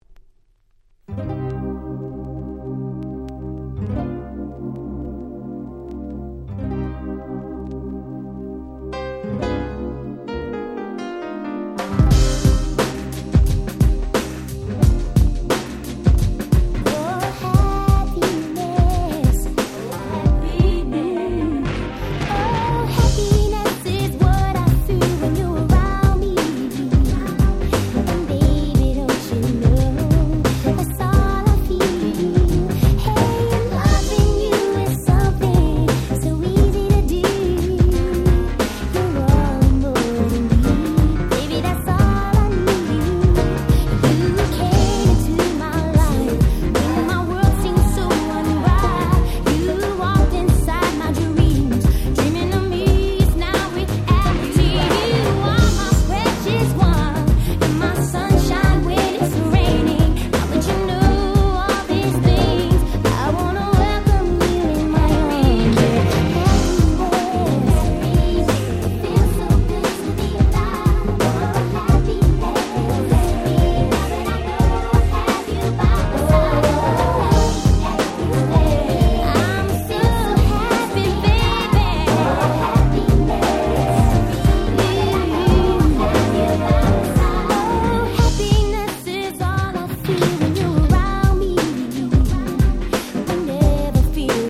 94' Very Nice R&B !!